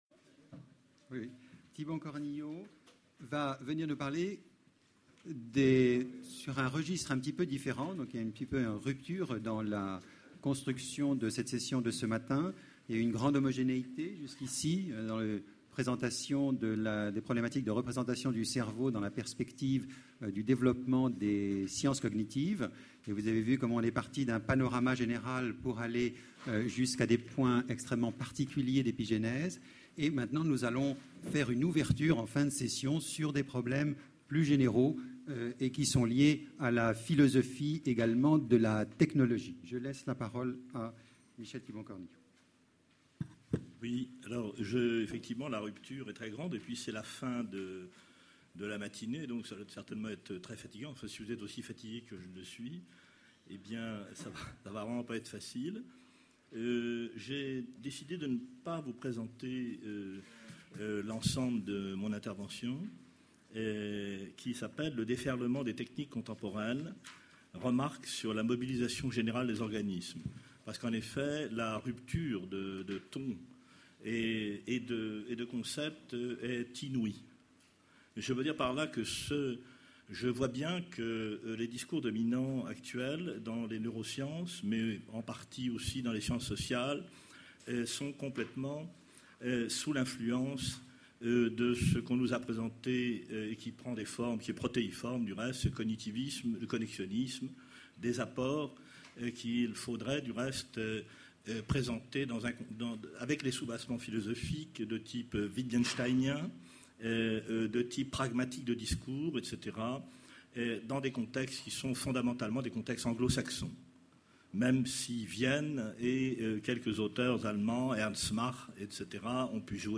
Colloque La représentation du vivant : du cerveau au comportement Session La représentation du cerveau par les neurosciences